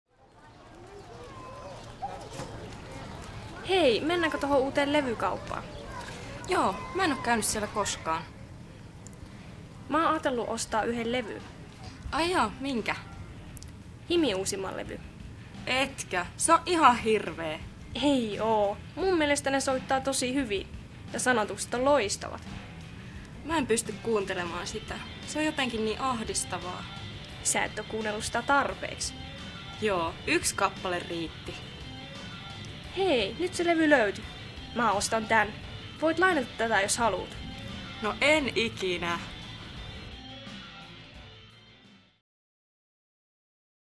Levykaupassa
How do the girls express their opinions in the conversation?